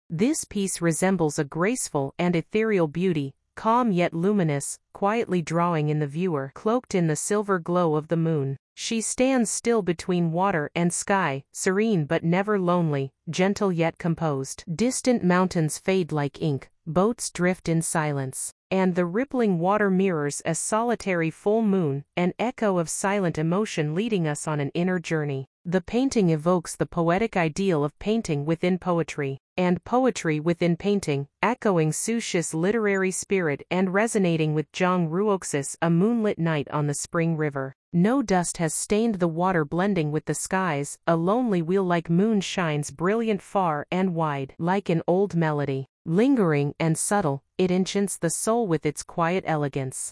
英文語音導覽